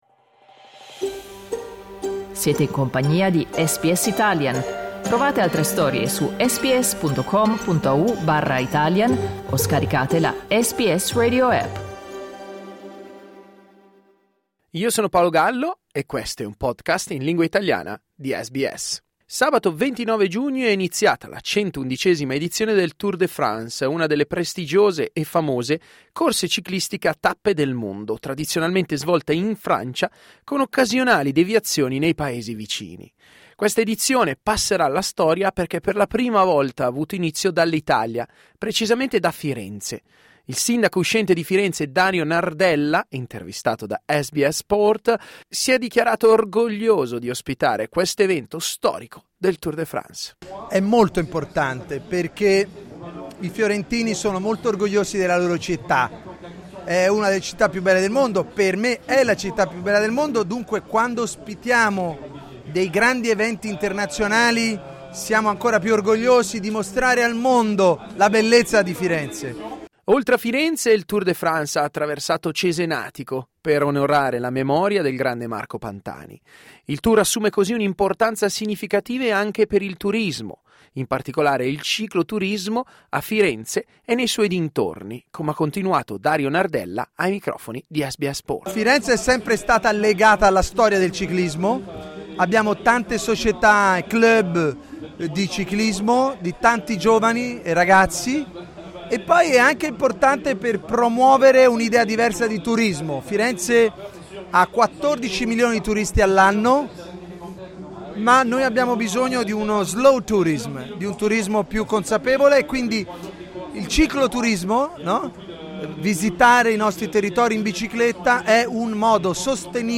Il sindaco uscente di Firenze, Dario Nardella, si è detto orgoglioso di ospitare questo evento storico del Tour de France. Ascolta l'intervista al sindaco uscente di Firenze cliccando sul tasto "play" in alto